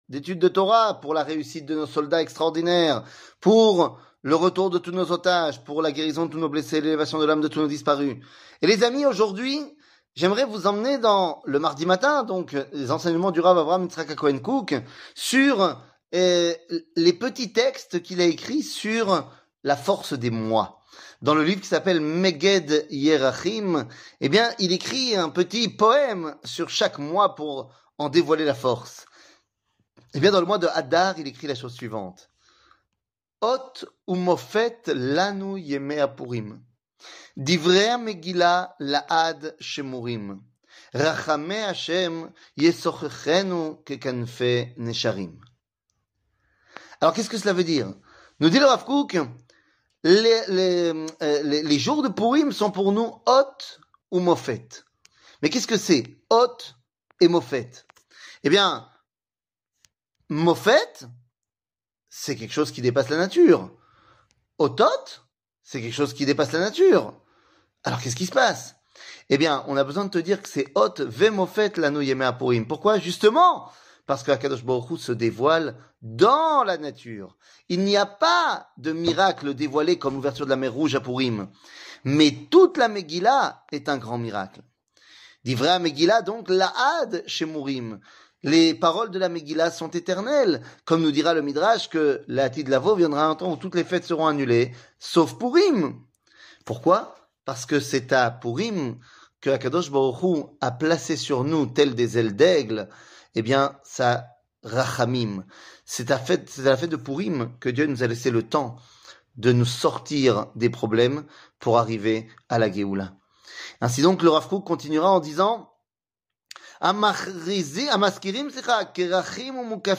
Rav Kook, le mois de Pourim, Meged Yerahim 00:04:21 Rav Kook, le mois de Pourim, Meged Yerahim שיעור מ 12 מרץ 2024 04MIN הורדה בקובץ אודיו MP3 (3.97 Mo) הורדה בקובץ וידאו MP4 (5.95 Mo) TAGS : שיעורים קצרים